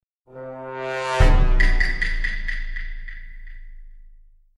1. Звук начала раунда Among Us
1-zvuk-nachala-raunda-among-us.mp3